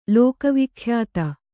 ♪ lōka vikhyāta